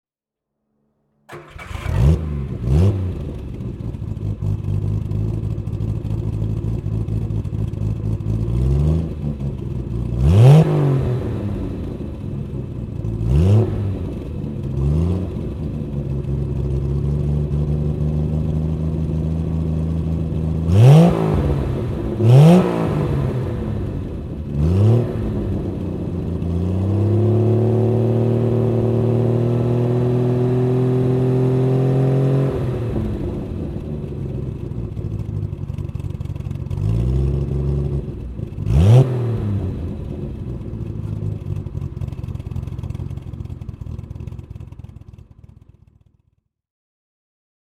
Bond Equipe GT 2 Litre (1970) - Starten und Leerlauf
Bond_Equipe_GT_2_Litre_1970.mp3